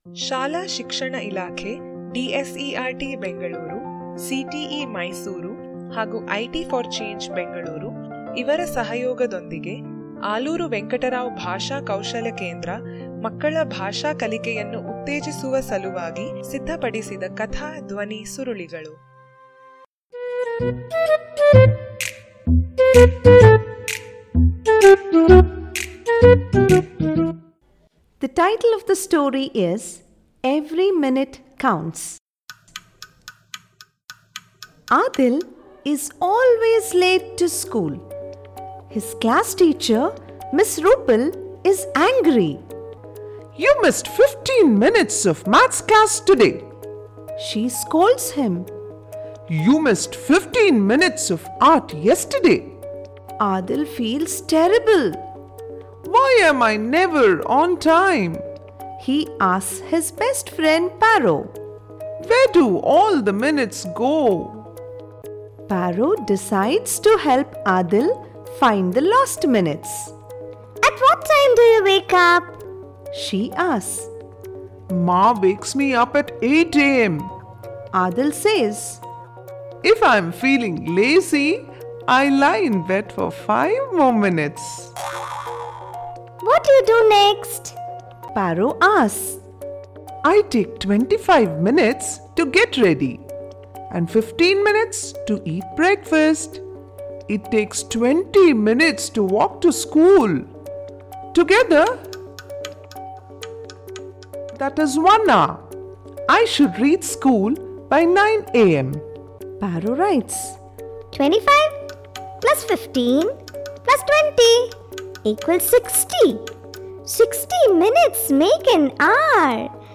Audio Story Link